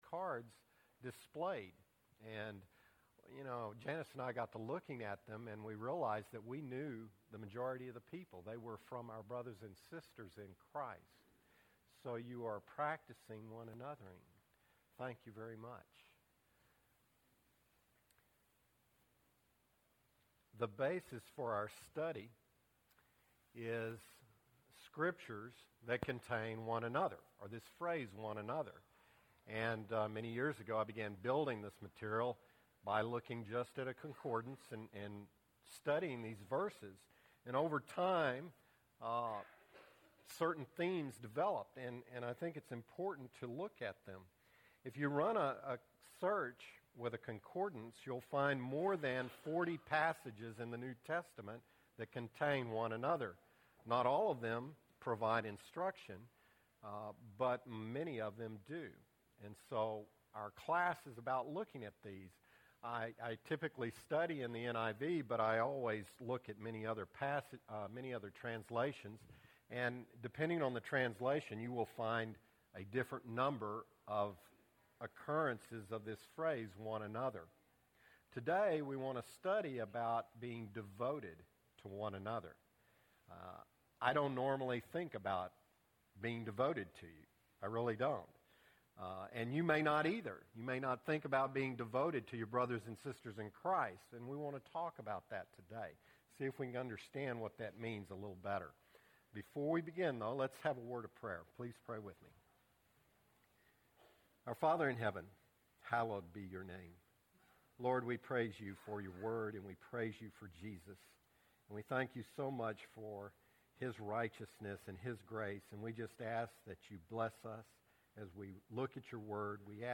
One Another-ing (3 of 13) – Bible Lesson Recording